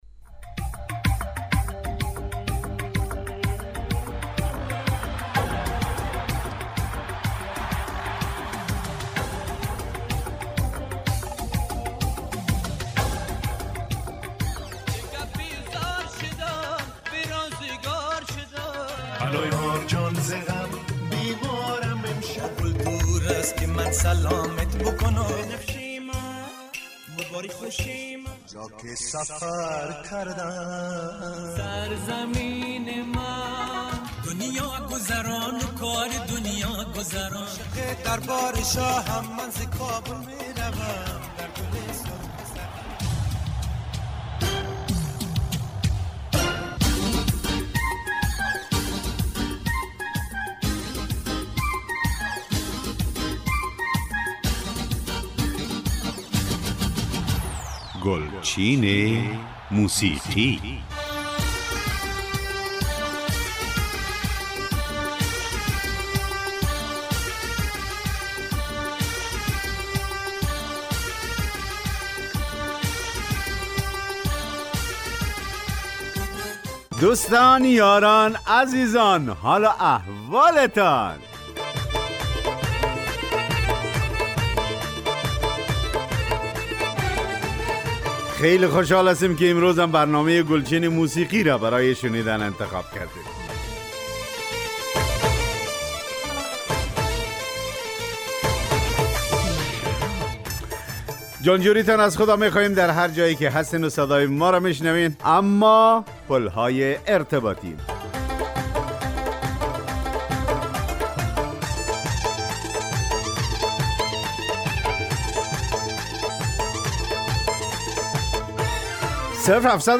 برنامه 29 ثور پخش ترانه های درخواستی شنونده ها